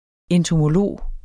Udtale [ εntomoˈloˀ ]